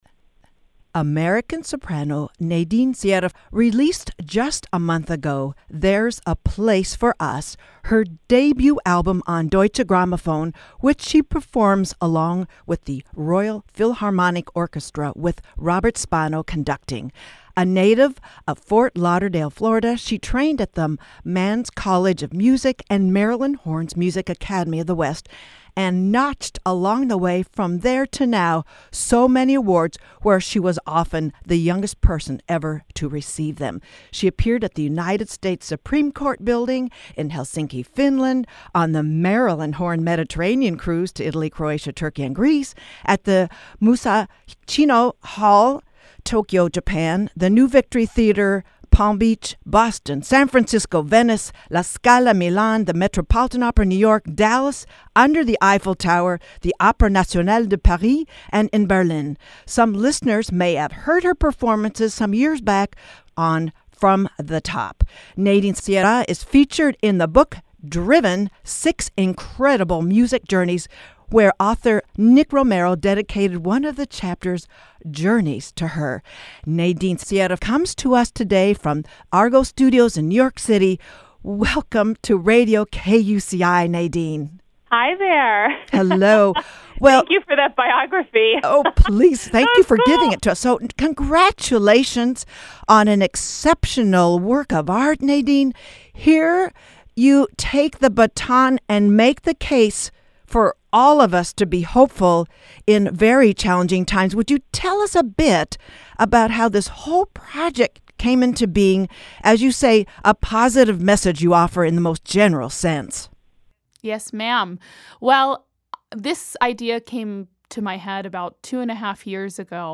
NadineSierraInterview9-28-18.mp3